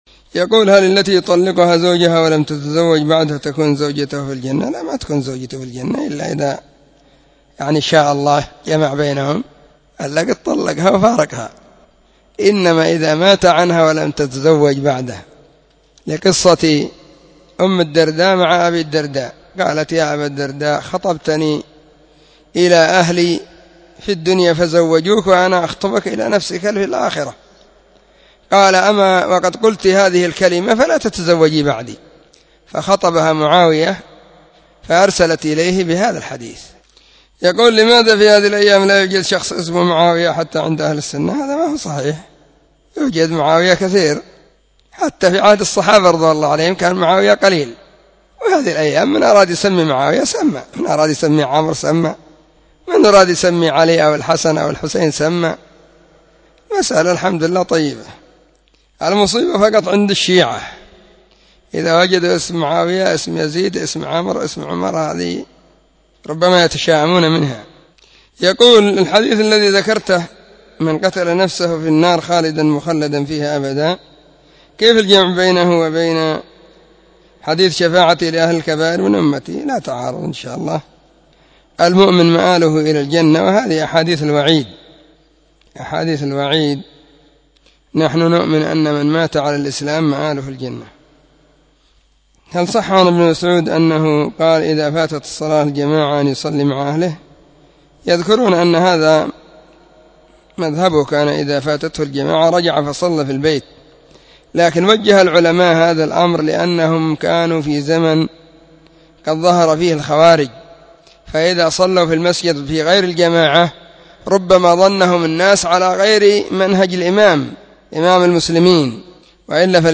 🔹 سلسلة الفتاوى الصوتية 🔸الأربعاء 10 /محرم/ 1443 هجرية. ⭕ أسئلة ⭕ -8